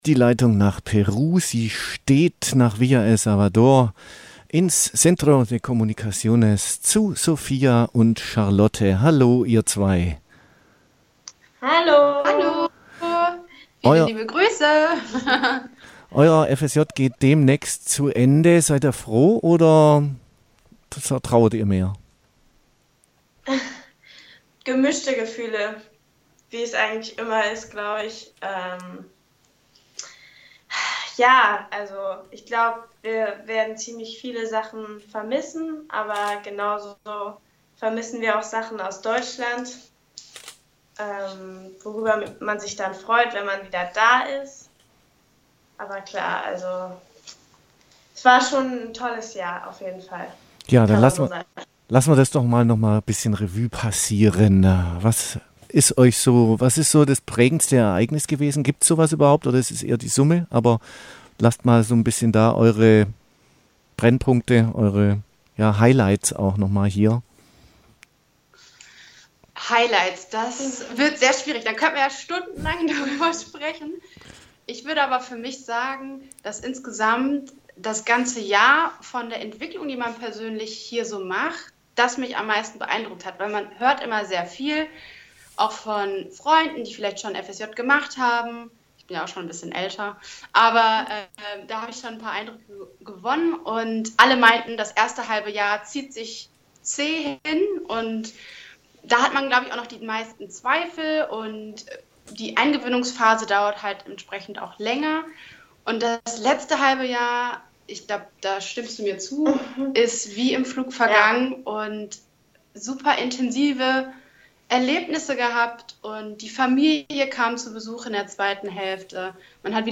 In der Liveschaltung ließen sie die vielfältigen Erfahrungen ihrer Zeit Revue passieren.